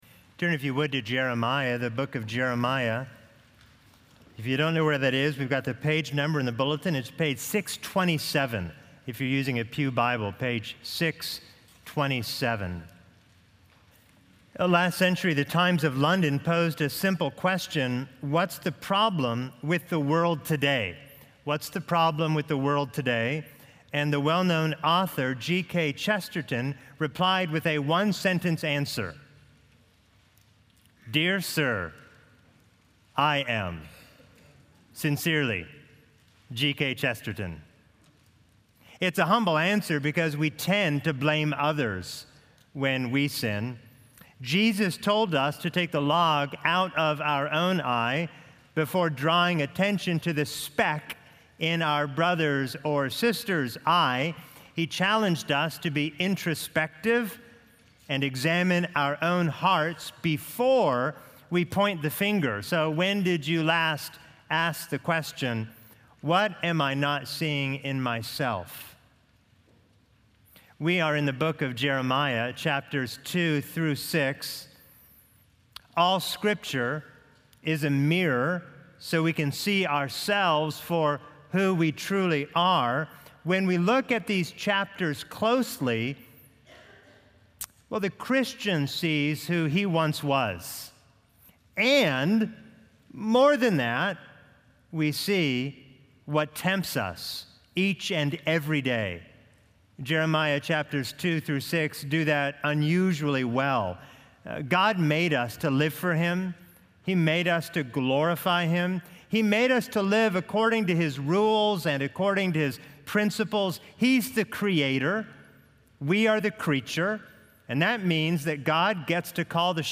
Video & Sermon Audio  Continue reading